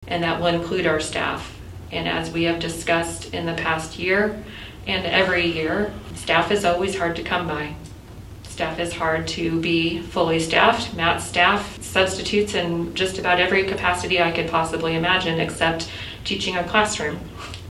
The board held a special meeting Wednesday night to adopt the plan before the start of classes.